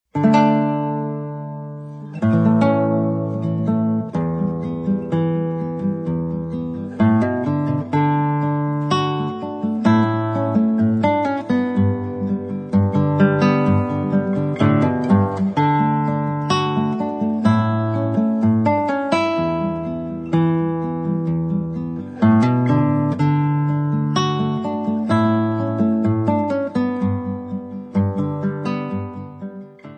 Besetzung: Gitarre